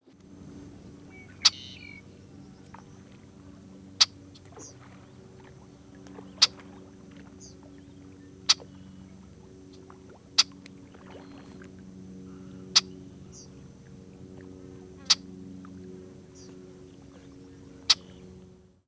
Red-winged Blackbird diurnal flight calls
Perched bird giving "jek" call.